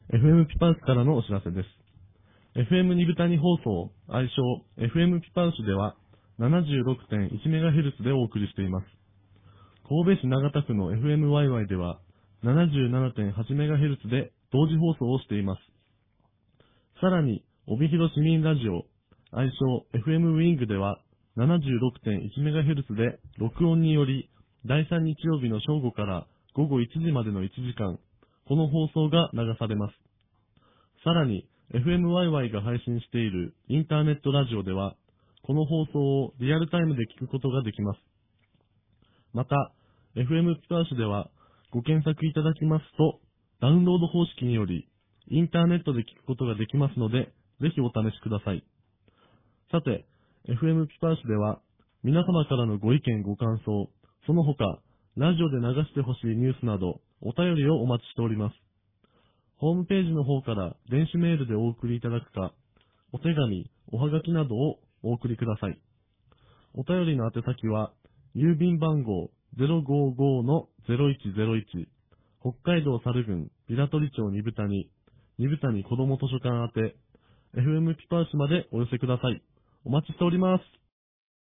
インタビューコーナー